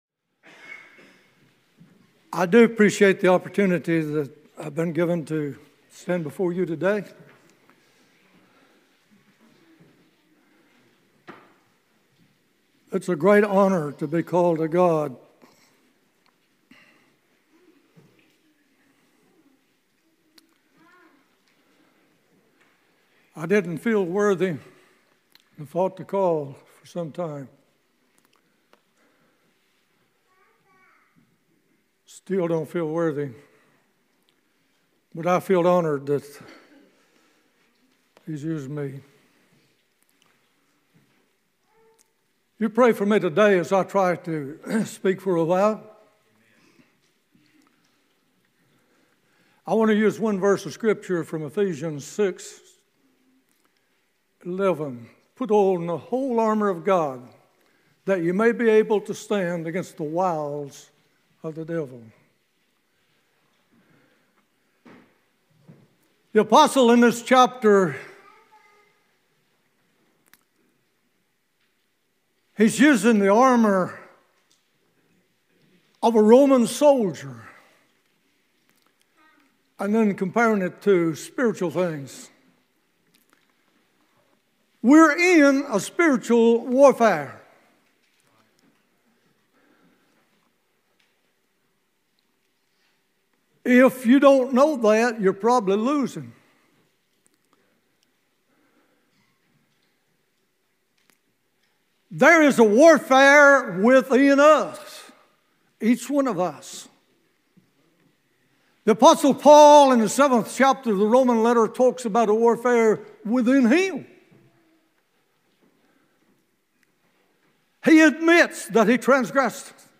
" Sunday Morning Messages " Sermons from our Sunday morning worship services.